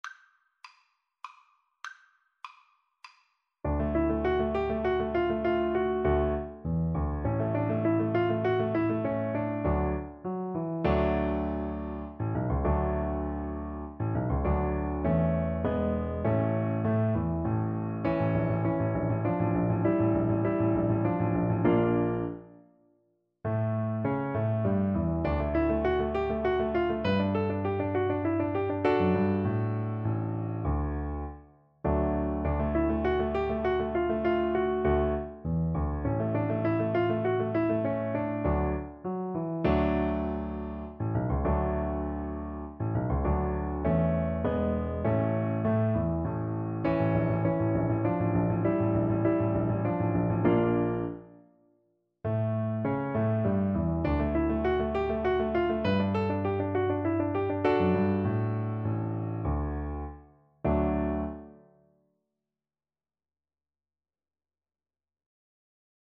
• Unlimited playalong tracks
D major (Sounding Pitch) (View more D major Music for Viola )
3/4 (View more 3/4 Music)
Classical (View more Classical Viola Music)